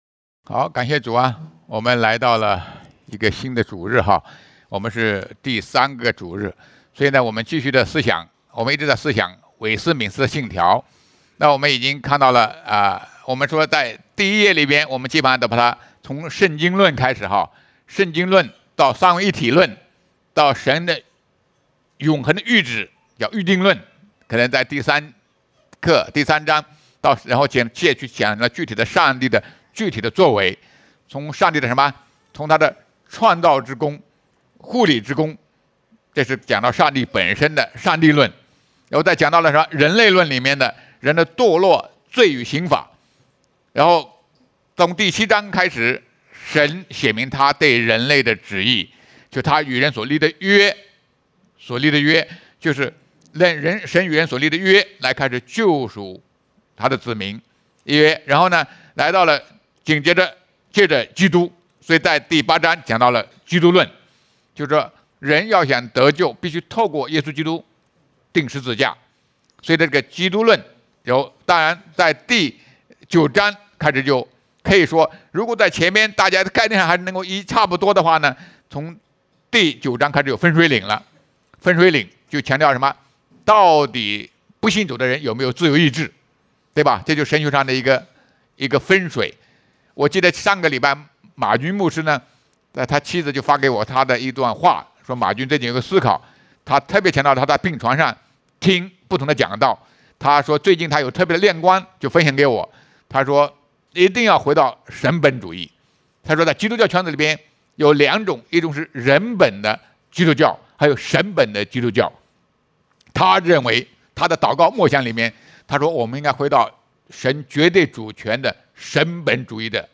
圣经讲道